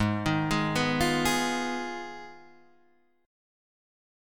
Abm6 chord